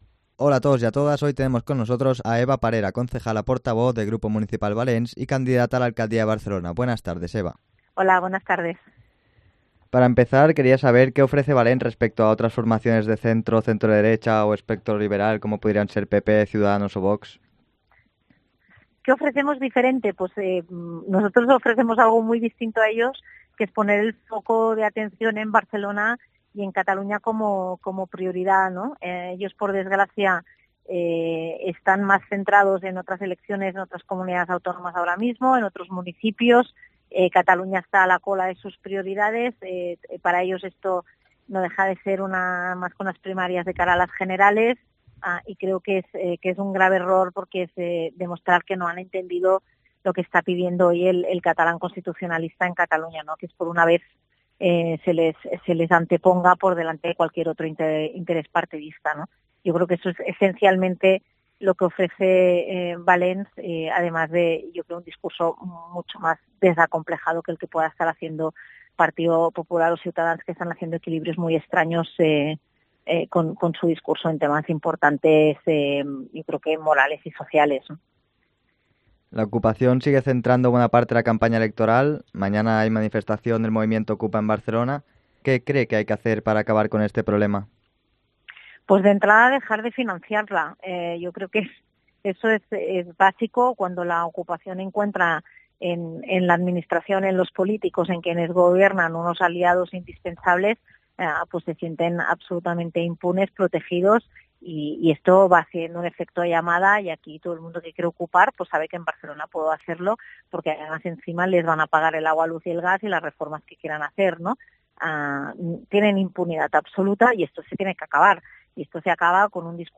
Entrevista a Eva Parera, candidata de Valents a la alcaldía de Barcelona